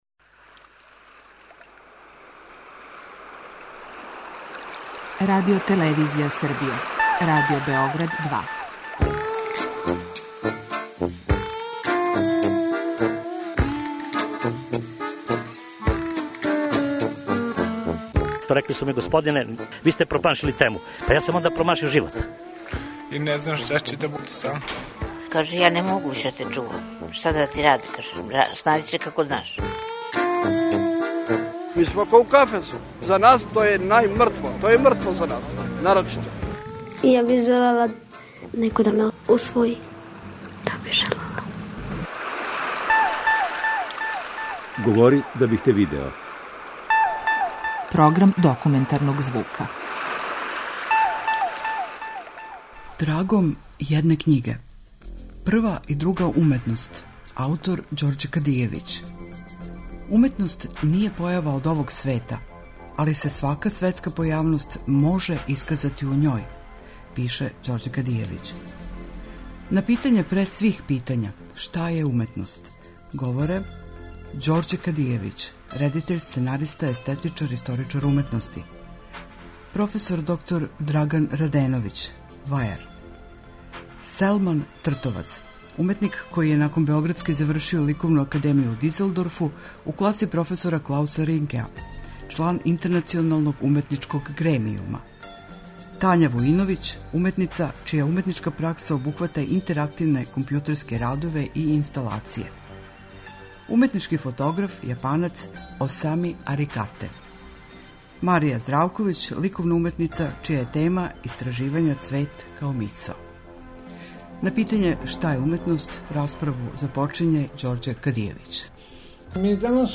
Документарни програм: Трагом једне књиге - Прва и друга уметност